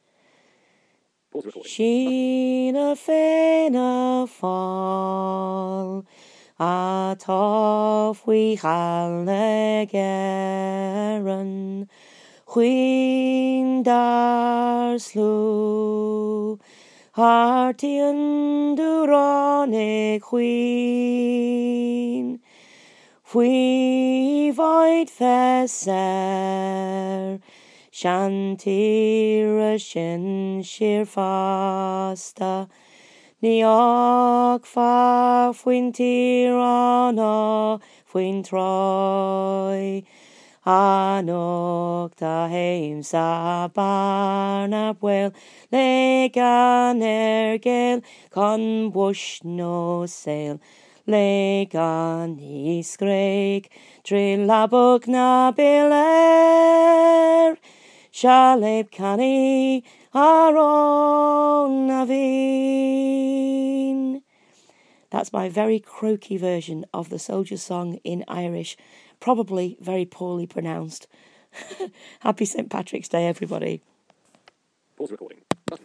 A croaky attempt at the soldier's song in Irish.